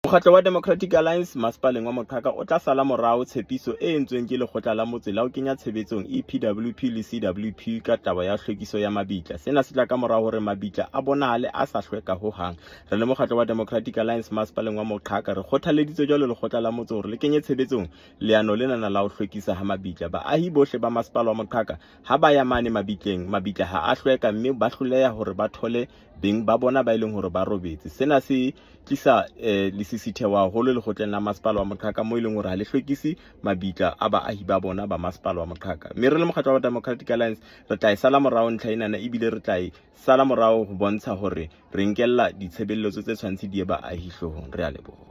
Sesotho soundbite by Cllr David Nzunga with images here, here, and here